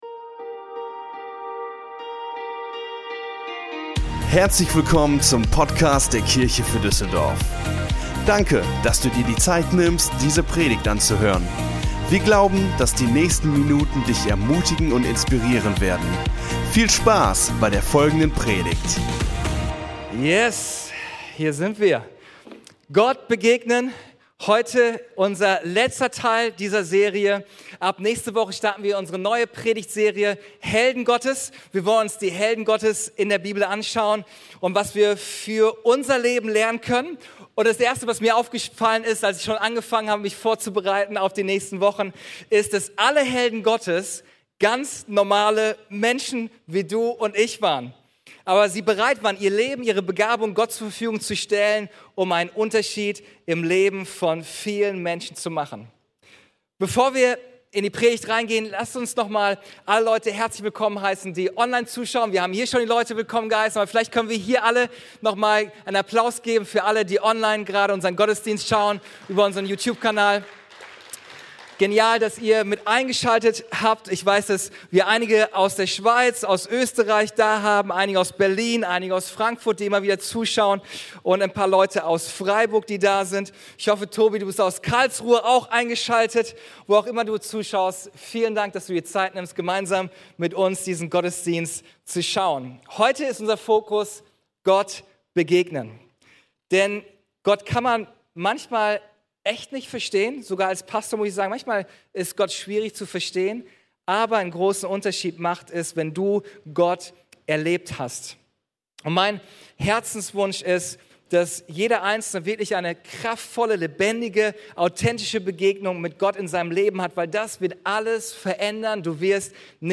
Der dritte Teil unserer Predigtserie: "Gott begegnen" Folge direkt herunterladen